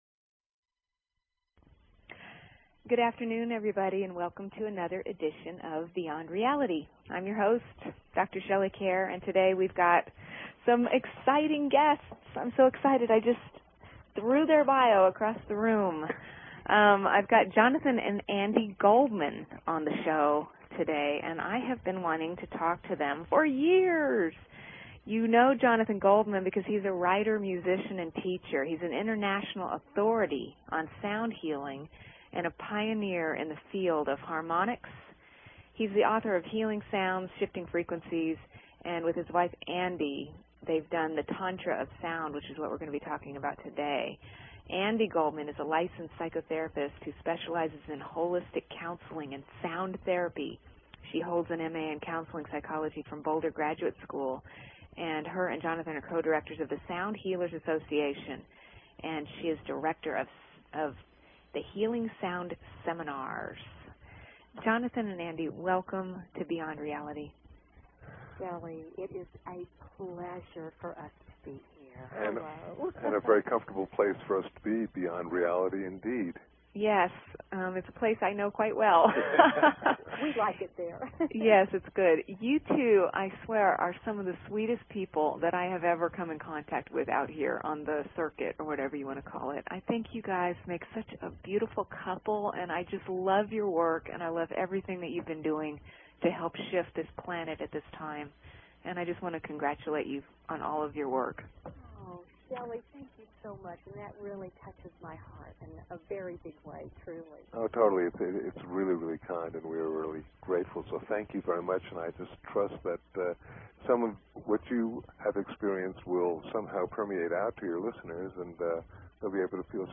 Talk Show Episode, Audio Podcast, Beyond_Reality and Courtesy of BBS Radio on , show guests , about , categorized as